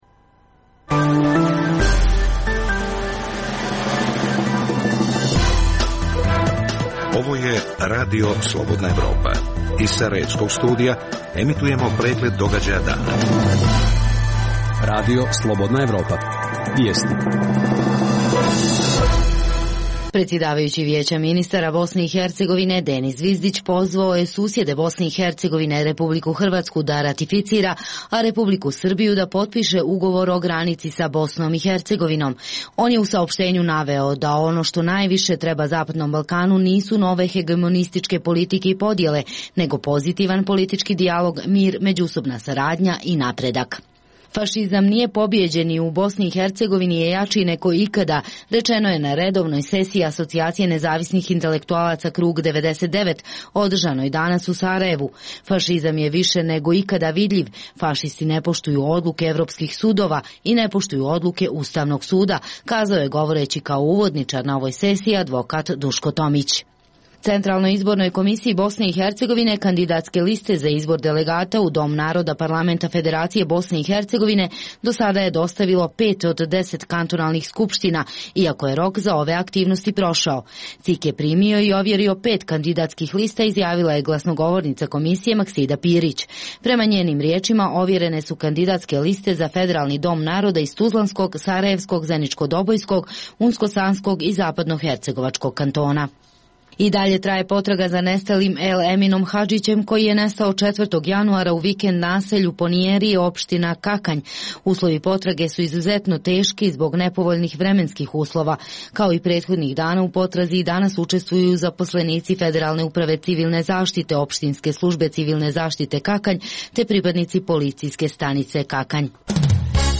Emisija sadrži vijesti, analize, reportaže i druge sadržaje o procesu integracije BiH u Evropsku uniju i NATO. Izdvajamo: Kakve su posljedice prakse kupovanja diploma u BiH?